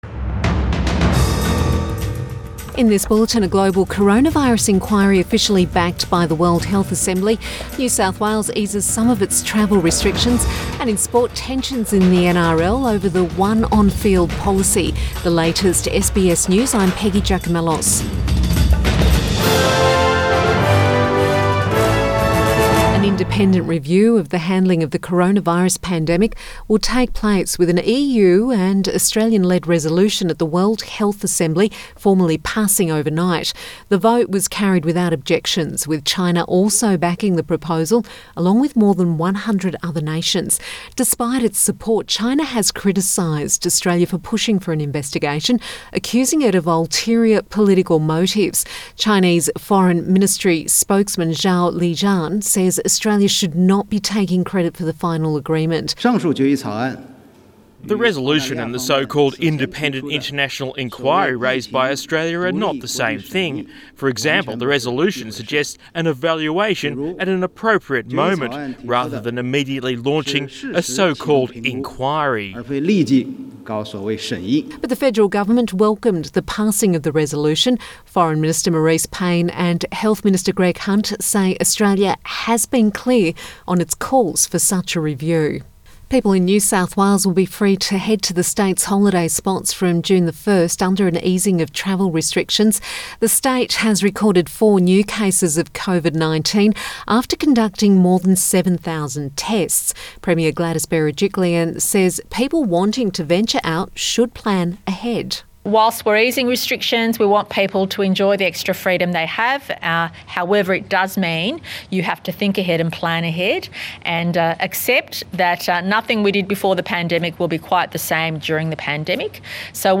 Midday bulletin May 20 2020